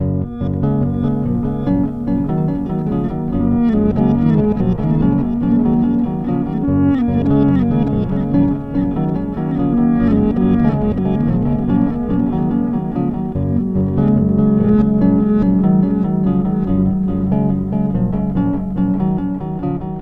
Recorded with a Fender Jazz bass and Hazarai unit.
Base loop  (0:20)